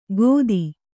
♪ gōdi